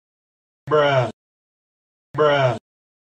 Bruh - Sound Effect
Category: Sound FX   Right: Personal